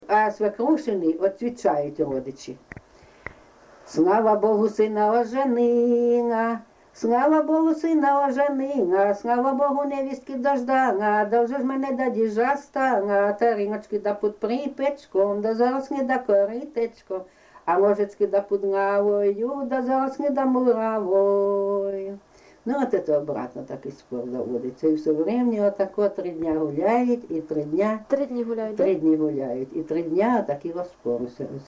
ЖанрВесільні
Місце записус. Курахівка, Покровський район, Донецька обл., Україна, Слобожанщина